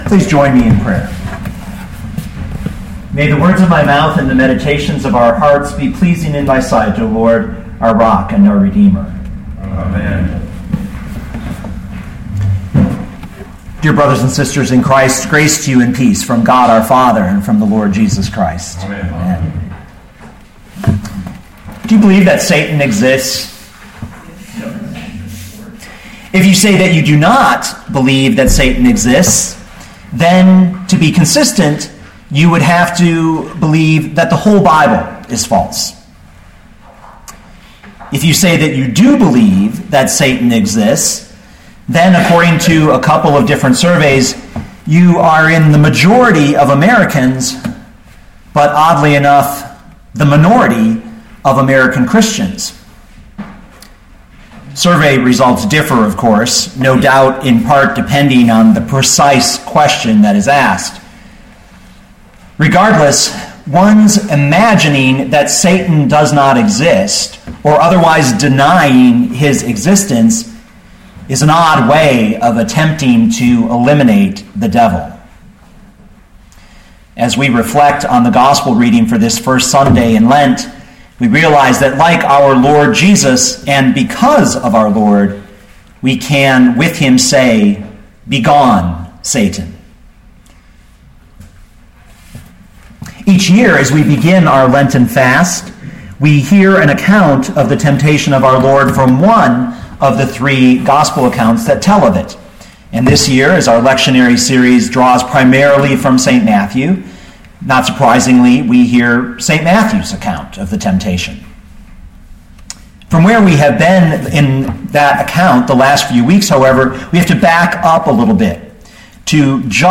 2014 Matthew 4:1-11 Listen to the sermon with the player below, or, download the audio.